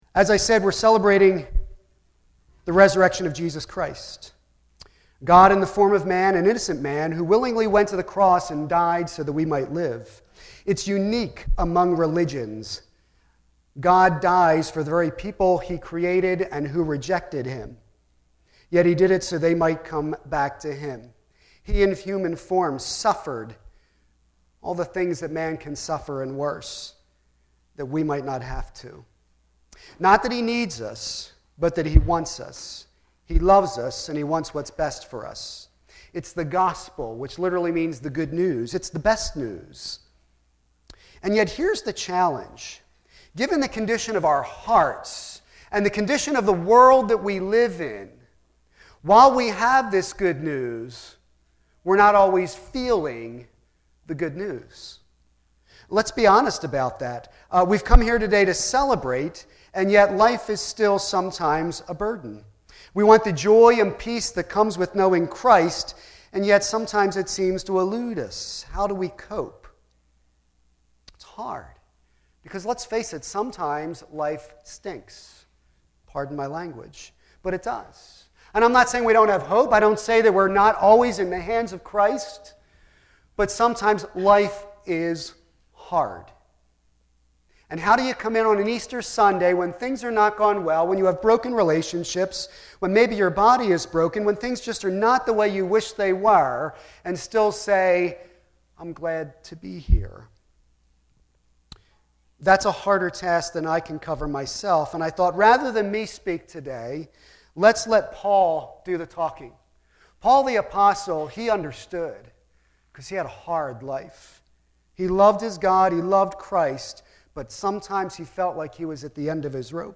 Series: Easter Sunday